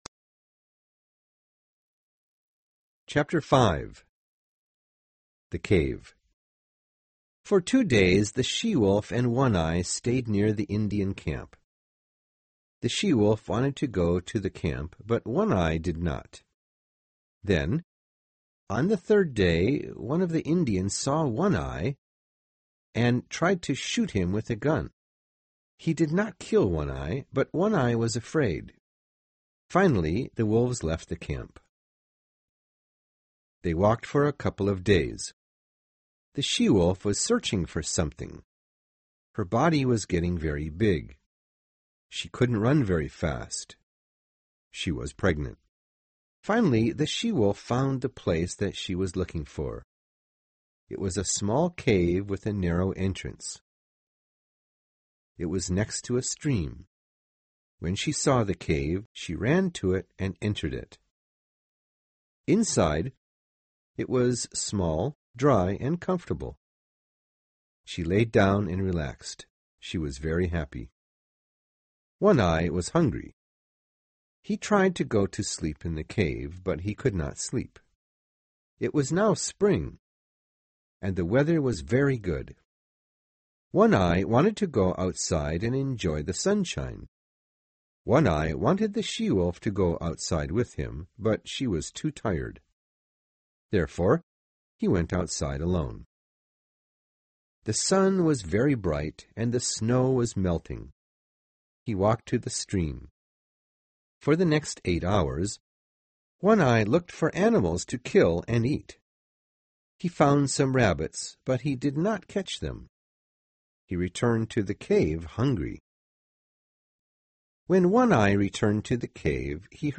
有声名著之白牙 05 听力文件下载—在线英语听力室